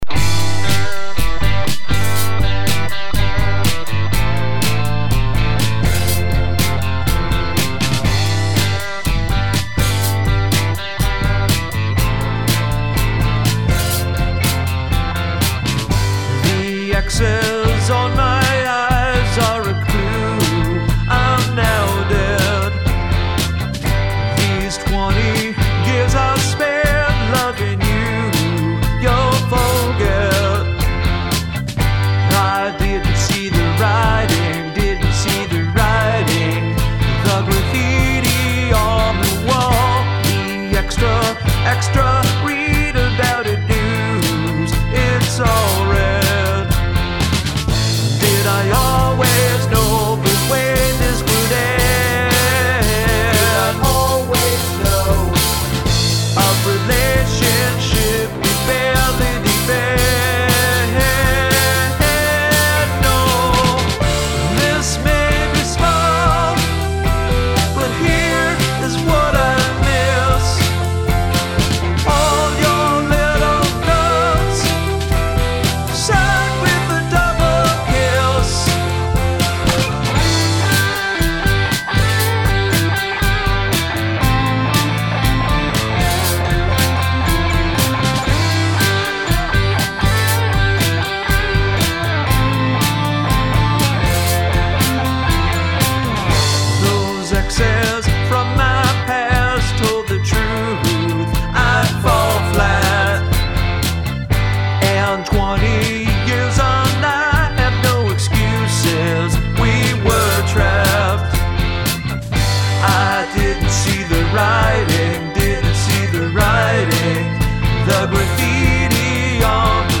Guitar, Lead Guitar
Guitar, Keyboards, Background Vocals
Drums, Vocals
Bass